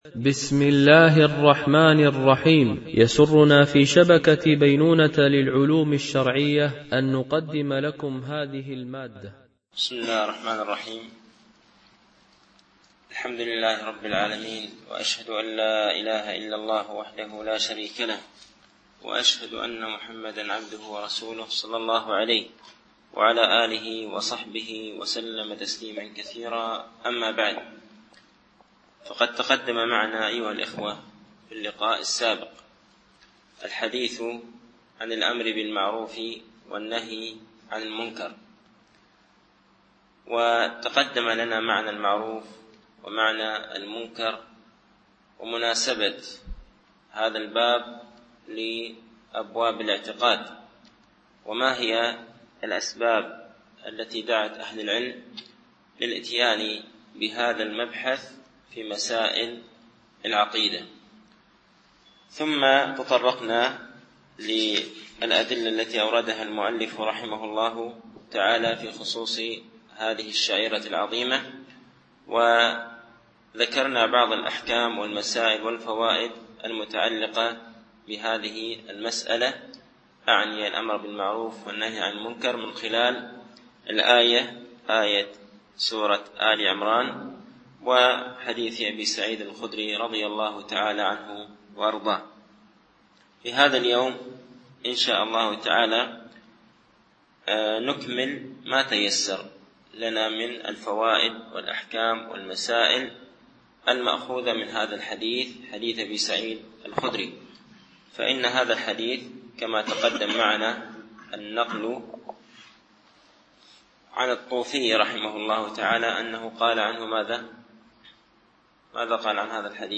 الألبوم: شبكة بينونة للعلوم الشرعية التتبع: 160 المدة: 64:15 دقائق (14.74 م.بايت) التنسيق: MP3 Mono 22kHz 32Kbps (CBR)